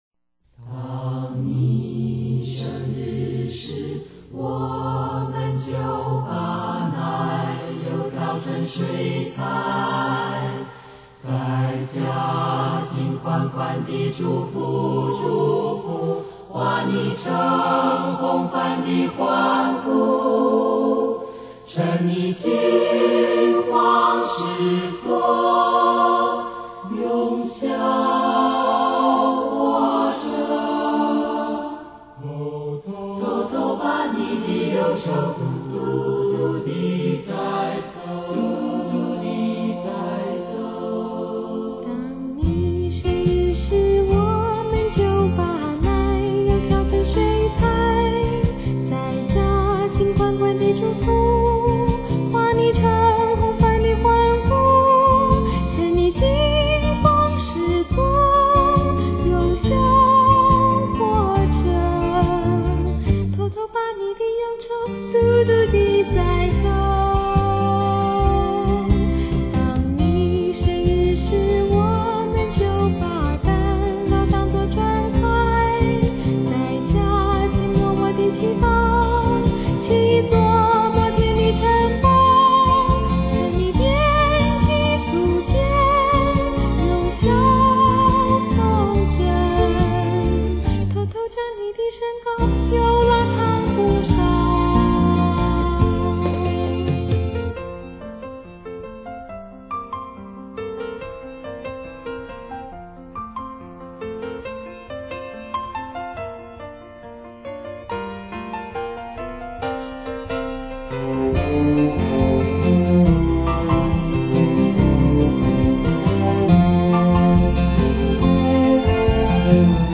正宗絕對原聲原唱!
典藏民歌時代全紀錄!
再現歌手當年清亮純淨嗓音。